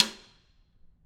Snare2-taps_v4_rr2_Sum.wav